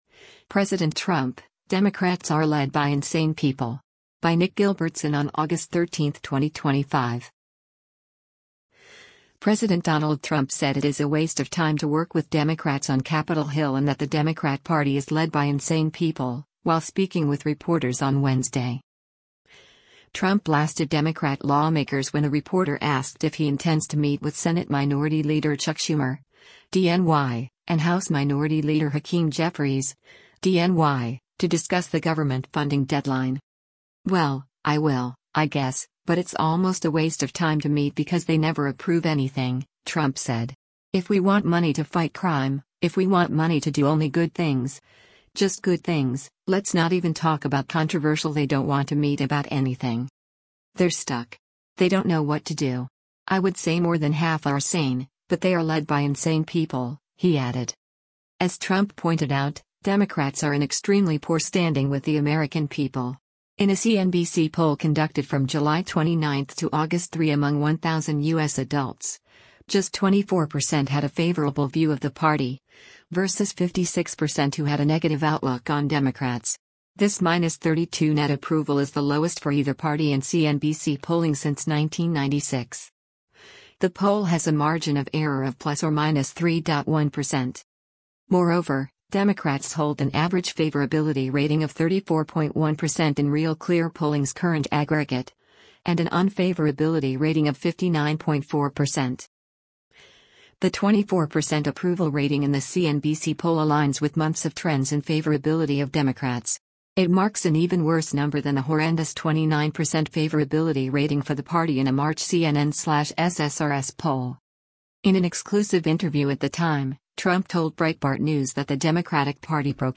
President Donald Trump said it is a “waste of time” to work with Democrats on Capitol Hill and that the Democrat Party is “led by insane people,” while speaking with reporters on Wednesday.